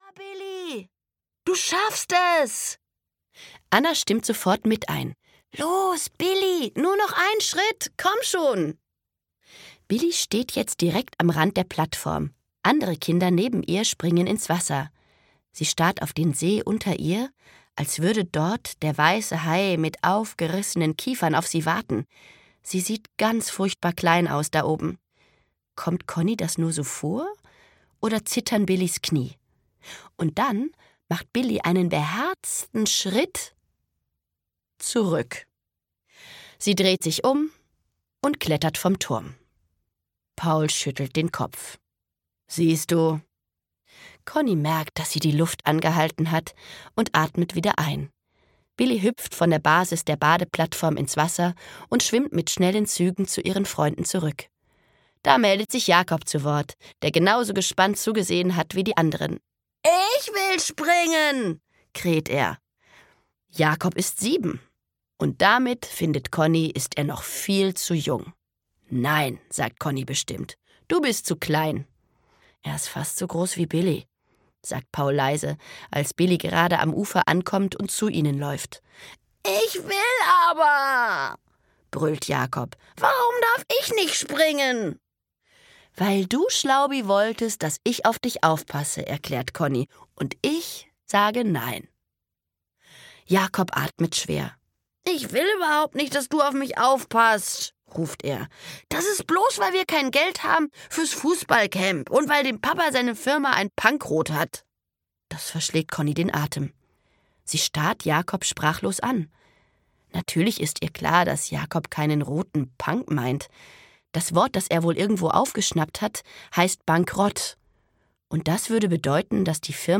Conni & Co: Conni & Co 2 - Das Hörbuch zum Film - Vanessa Walder - Hörbuch